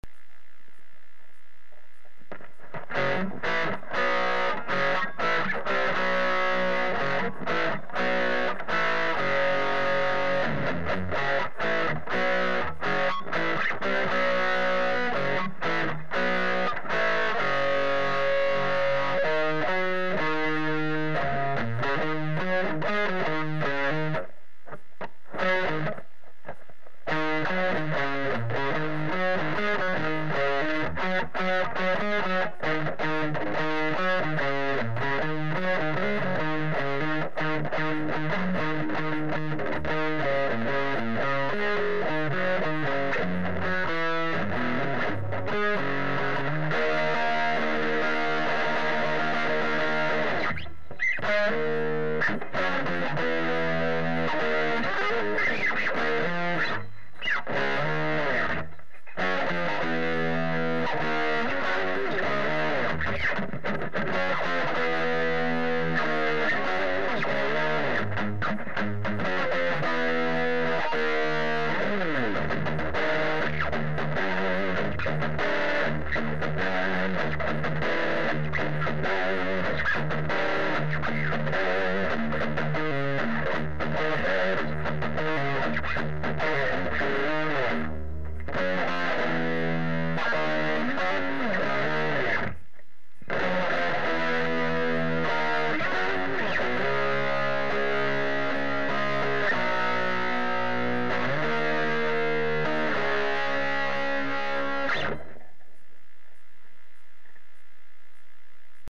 I plugged the head into a Traynor Bass cab with a single 8ohm 15 speaker
Reminder: These were recorded after using an attenuator into a 15" speaker.